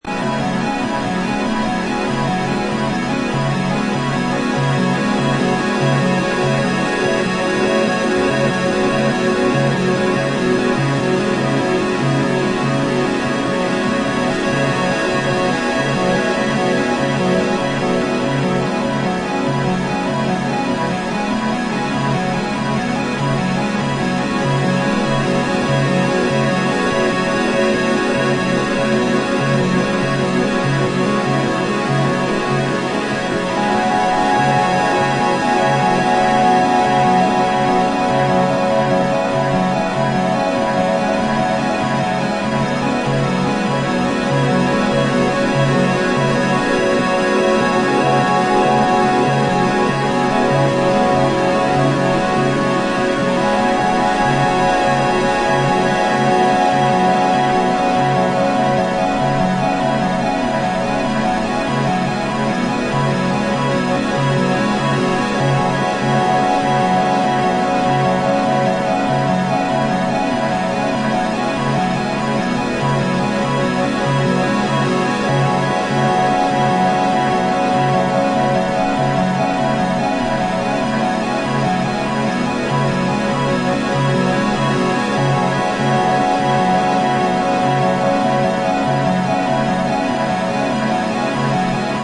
Electro Electronix